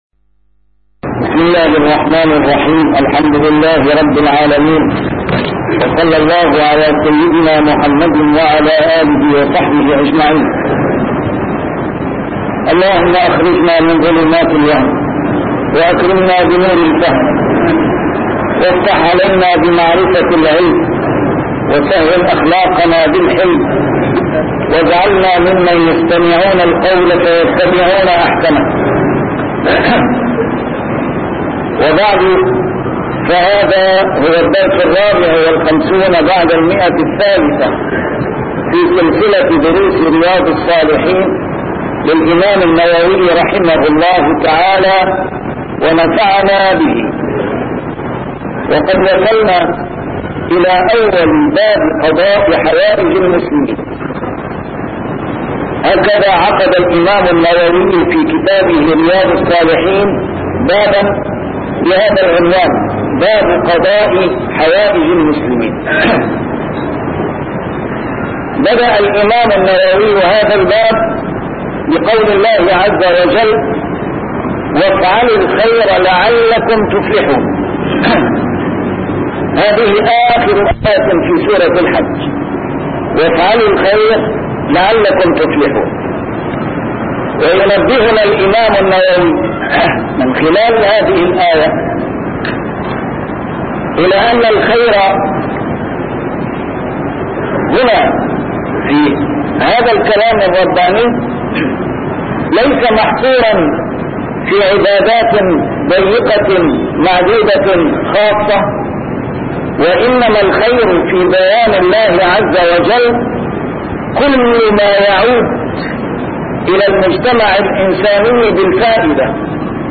شرح كتاب رياض الصالحين - A MARTYR SCHOLAR: IMAM MUHAMMAD SAEED RAMADAN AL-BOUTI - الدروس العلمية - علوم الحديث الشريف - 354- شرح رياض الصالحين: قضاء حوائج المسلمين